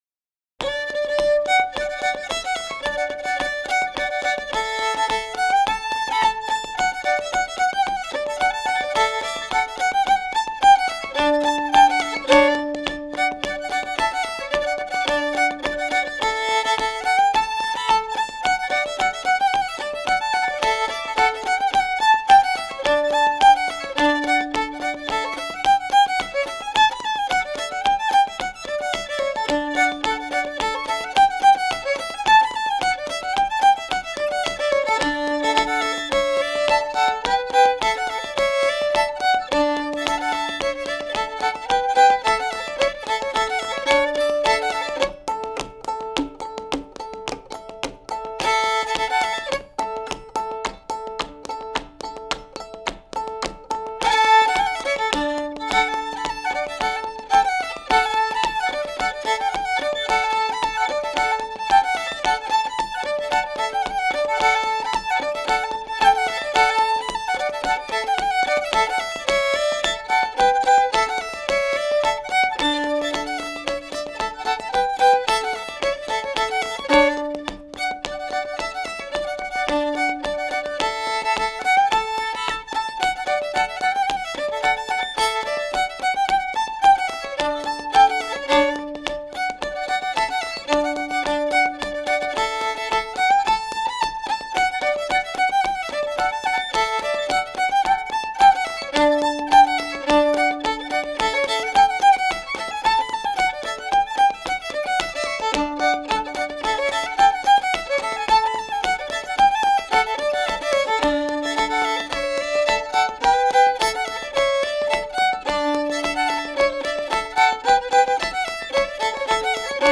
Version instrumentale
Pièce musicale éditée